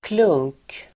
Uttal: [klung:k]